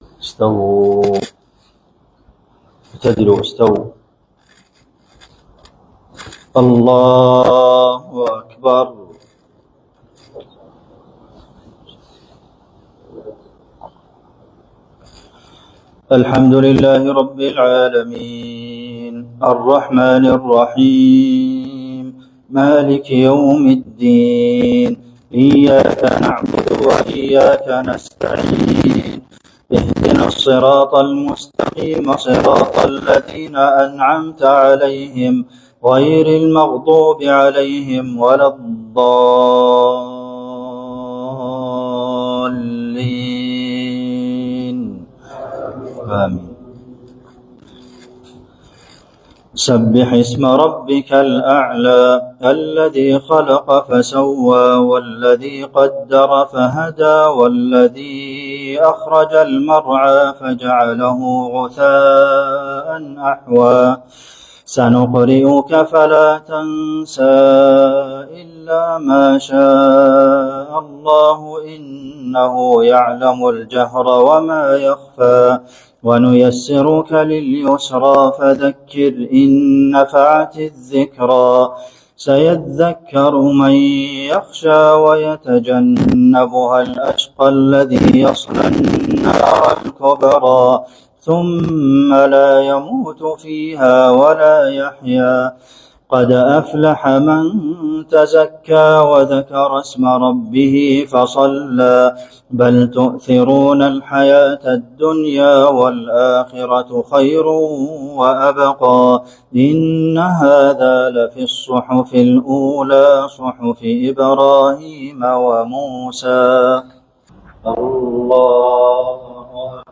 صلاة الجمعة 24 ربيع الأول 1446هـ | من المسجد الجامع بلوساكا ، زامبيا > زيارة الشيخ عبدالمحسن القاسم لـ جمهورية زامبيا > تلاوات و جهود الشيخ عبدالمحسن القاسم > المزيد - تلاوات الحرمين